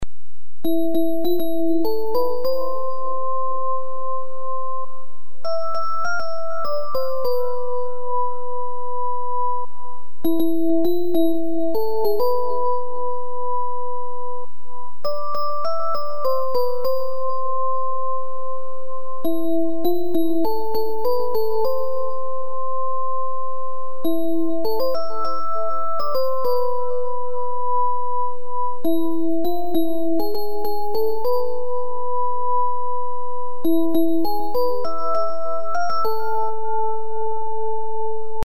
(두곡 16마디를 미디 음악으로 멜로디 연주한 파일)